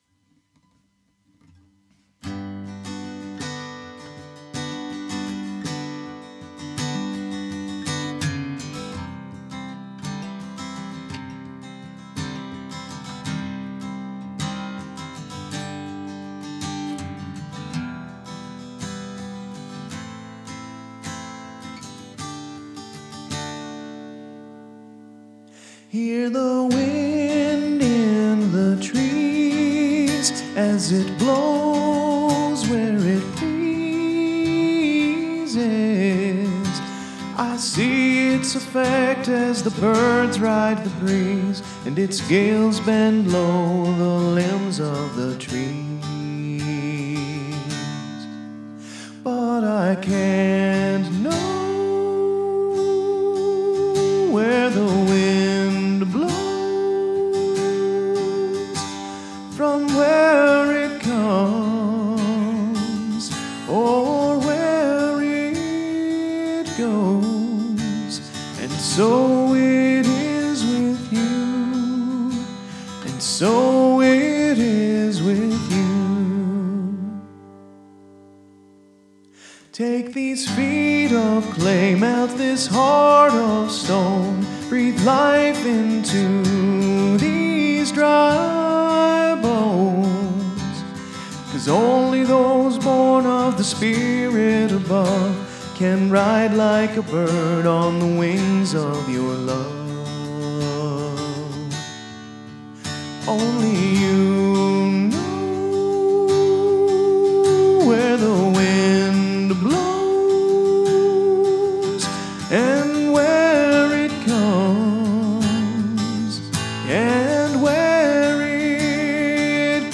The circle progressions in the last two lines of the verses, the secondary dominants in the chorus and the modulation in the bridge are all direct applications of techniques I learned in theory.
The bad: I started out trying to emulate the wind with my guitar… but with limited time- 1 day!- it’s hard to bring all the ideas to fruition!
I think it ended abruptly… and I wish I’d had more time to write the last verse.
EQ’ing and mixing can be the downfall of an otherwise great song!